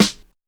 just blaze airsnare.WAV